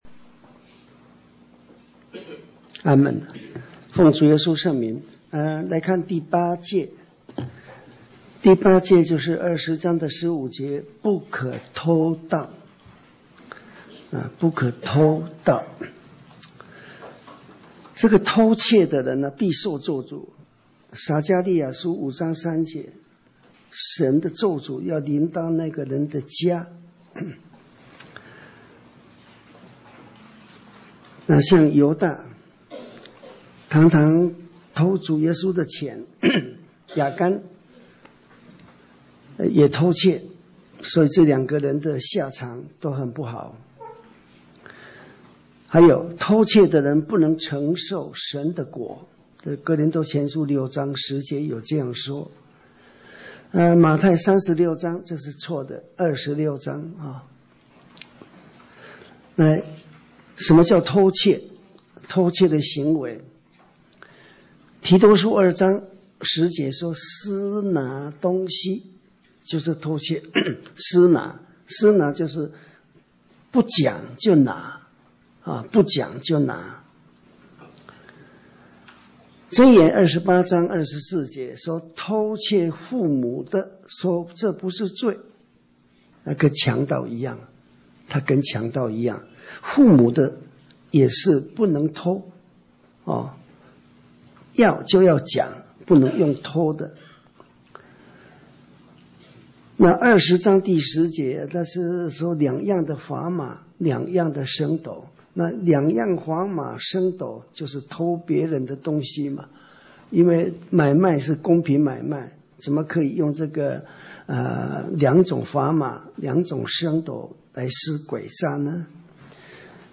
講習會
地點 台灣總會 日期 02/17/2014 檔案下載 列印本頁 分享好友 意見反應 Series more » • 出埃及記 22-1 • 出埃及記 22-2 • 出埃及記 22-3 …